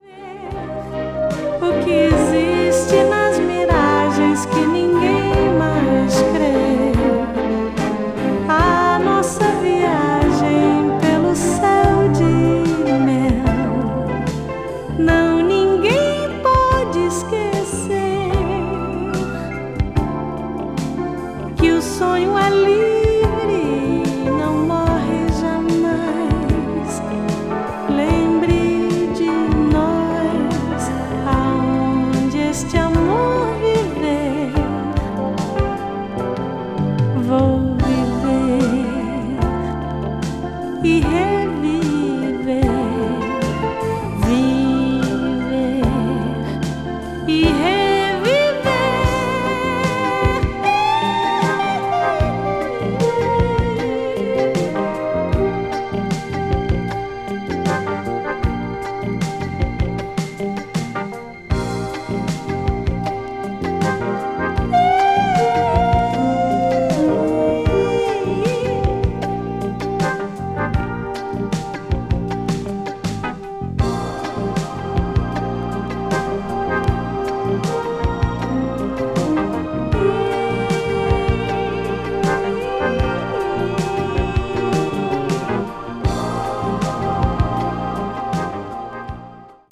a.o.r.   brazil   mellow groove   mpb   pop   world music